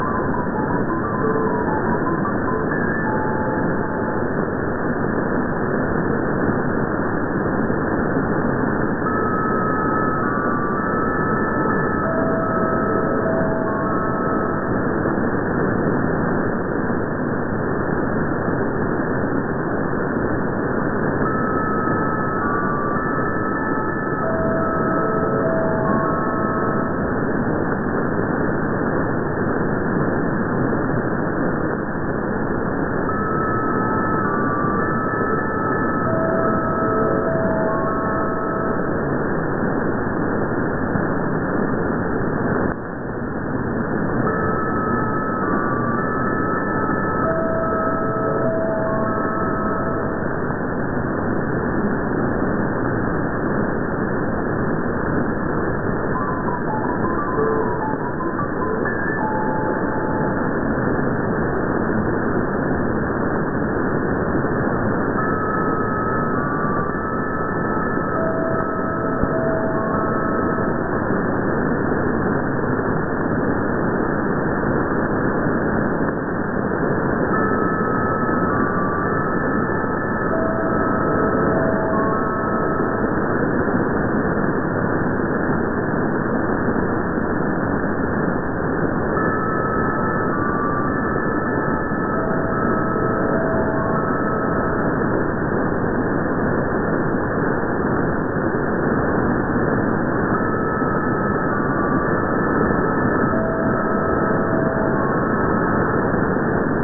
I have picked up in Southern Sweden this station with a repetitive interval signal on 1449 kHz. I have been told it is located somewhere in northern Italy.